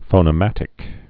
(fōnĭ-mătĭk)